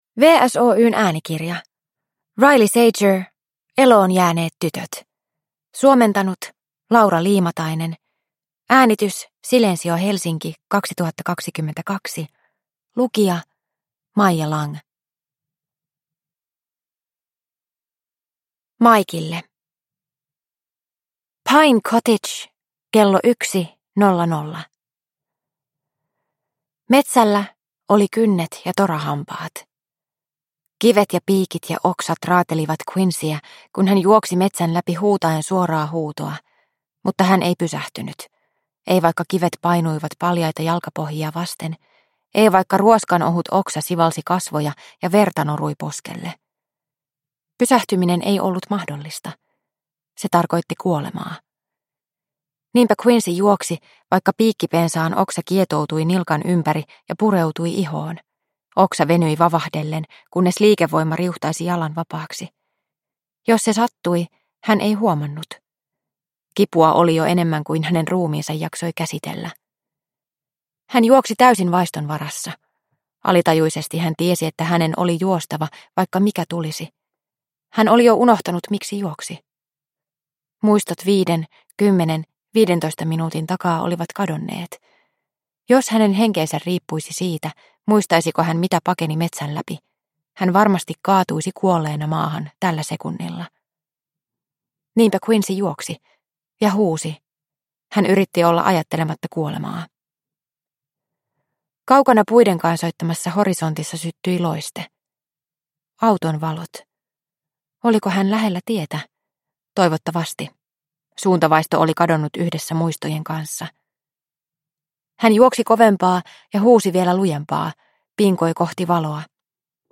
Eloonjääneet tytöt – Ljudbok – Laddas ner